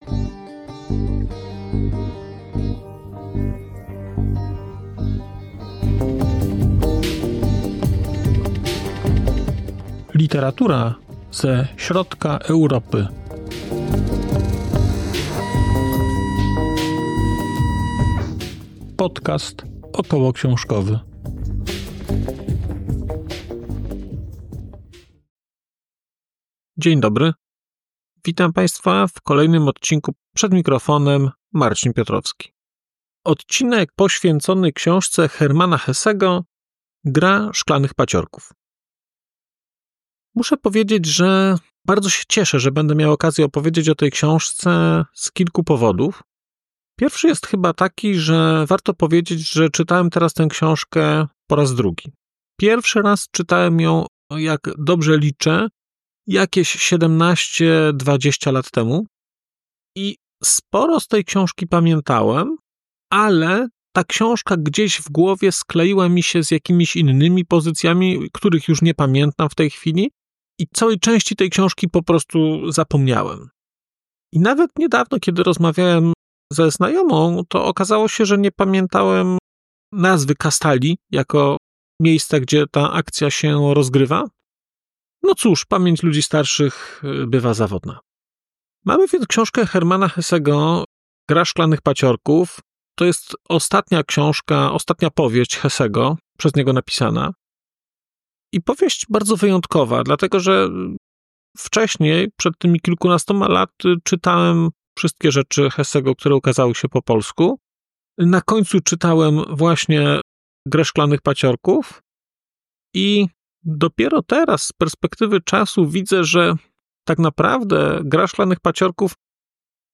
🔧 odcinek zremasterowany: 6.04.2025